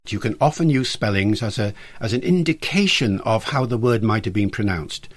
So, again we are left with just a schwa: /ə/.
ǀ maɪt ə biːn prəˈnaʊnstǀ